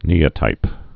(nēə-tīp)